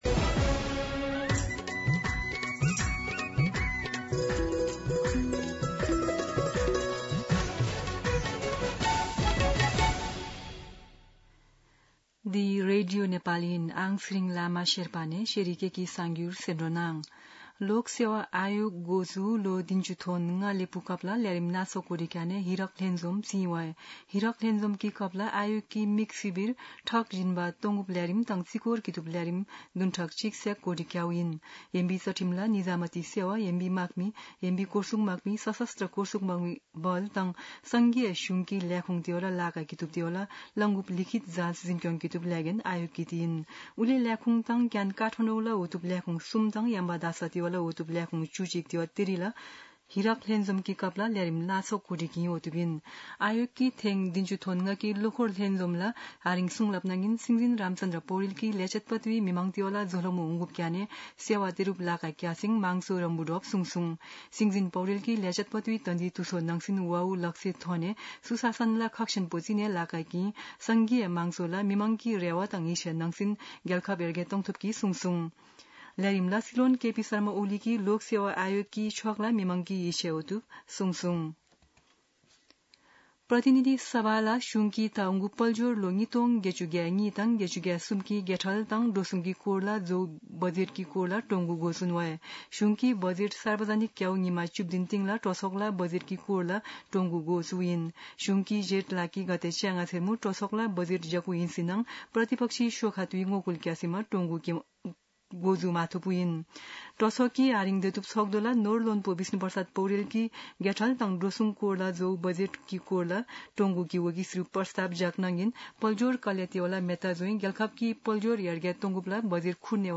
शेर्पा भाषाको समाचार : १ असार , २०८२
Sherpa-News-01.mp3